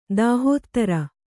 ♪ dāhōttara